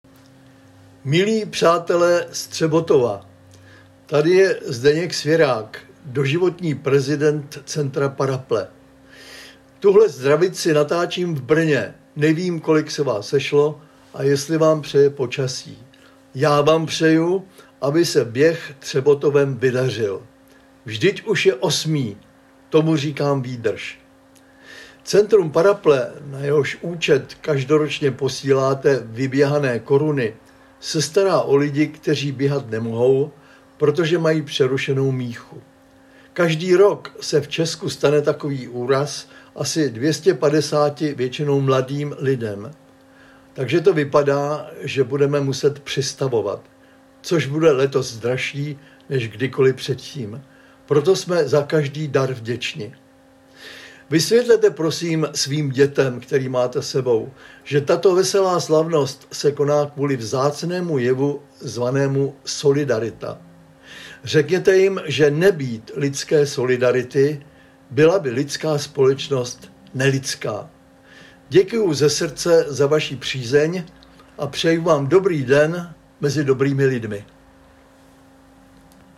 Zdravice Zdeňka Svěráka: